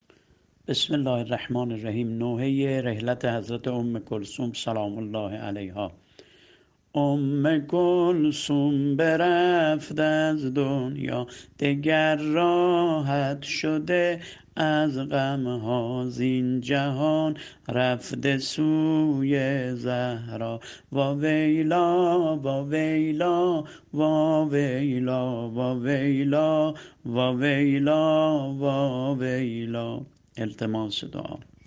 متن شعر نوحه حضرت ام کلثوم سلام الله علیها -(ام کلثوم برفت از دنیا )